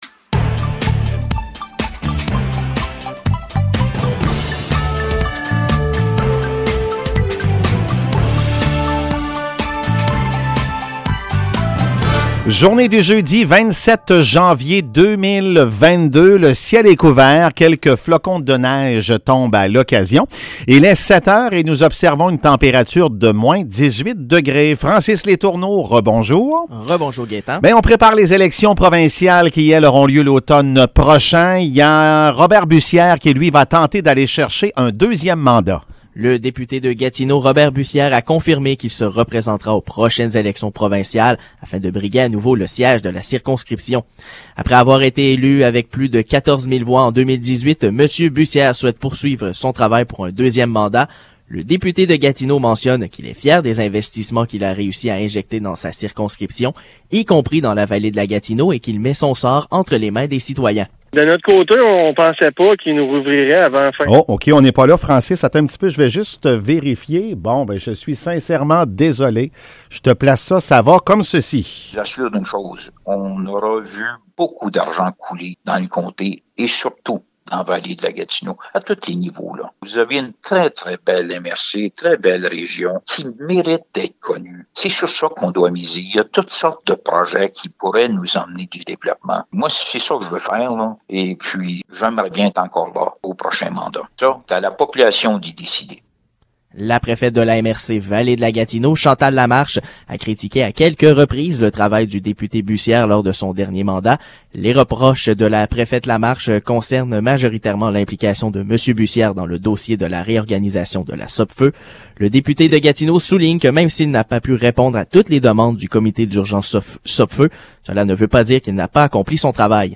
Nouvelles locales - 27 janvier 2022 - 7 h